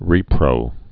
(rēprō)